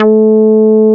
P MOOG A4MP.wav